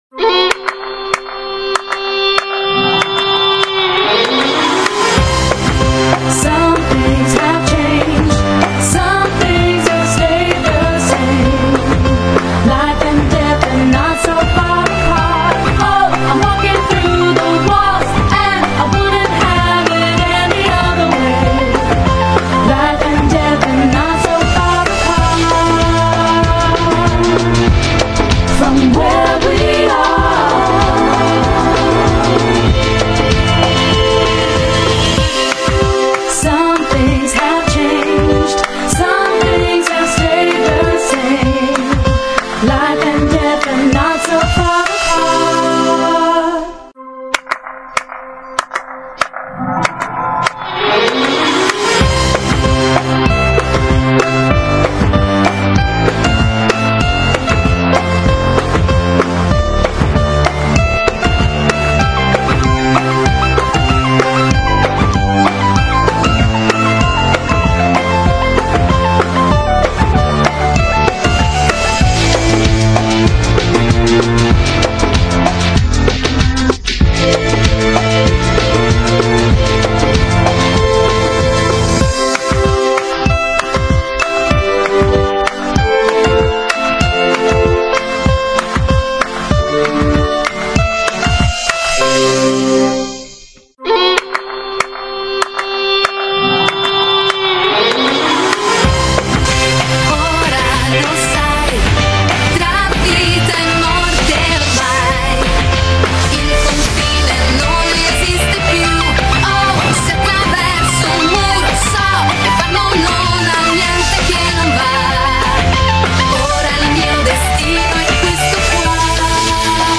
Dubs Only, 4 Languages